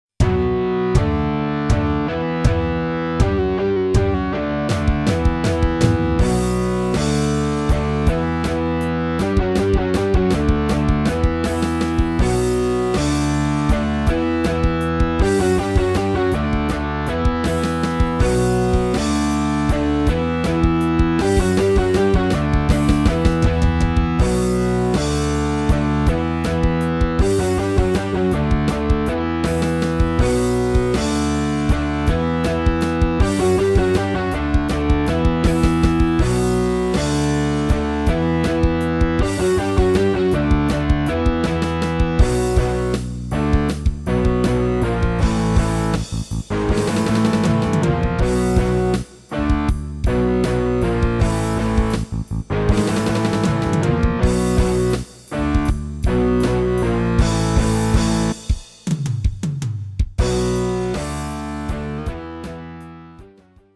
そのオリジナルに各ヘッドホンプロセッサで音響処理を加えたものを再び録音し、音声圧縮しています。
Headroom Cosmicで処理　PROCESS SW=ON FILTER SW=OFF
オリジナル音声や、各種ヘッドホンプロセッサからの音声は、ONKYO WAVIO SE-U77を使って録音しています。